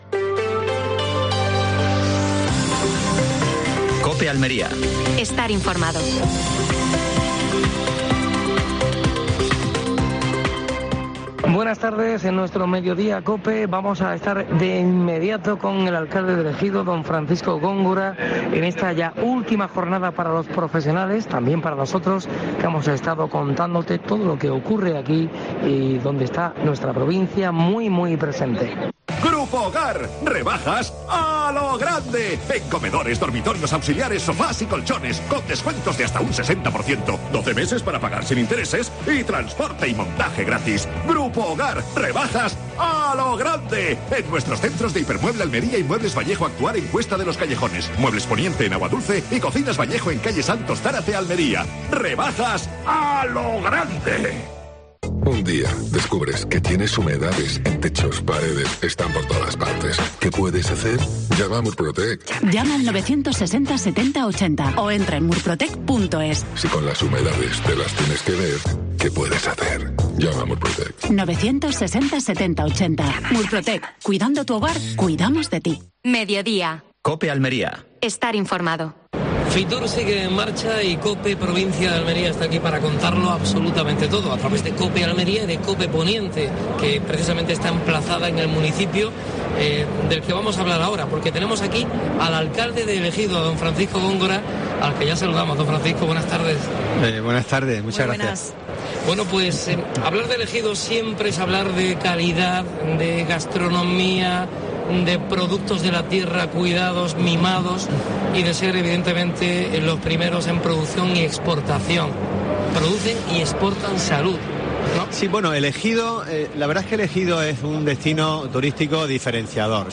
AUDIO: Última hora en Almería. Entrevista a Francisco Góngora (alcalde de El Ejido) en FITUR.